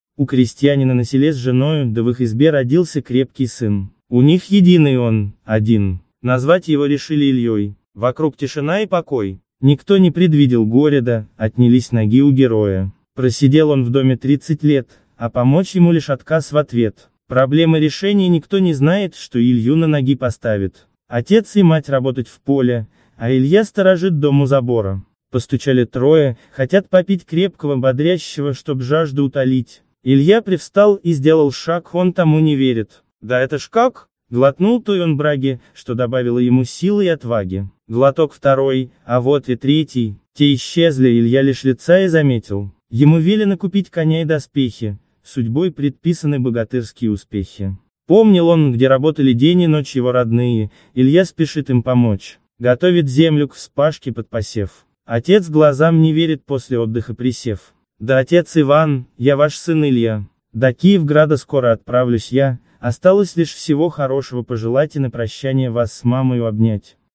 Аудиокнига Богатыри земли русской. Поэма | Библиотека аудиокниг